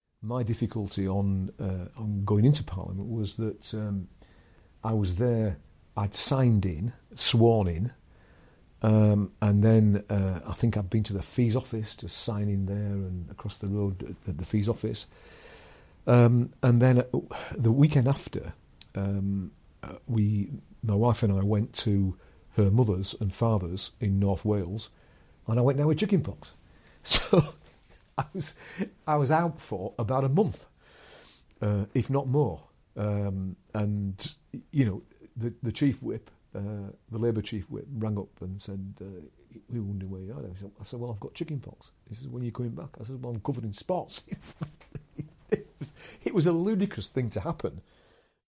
In this clip former Labour MP David Hinchliffe discusses the surprising setback he faced soon after election: chicken pox!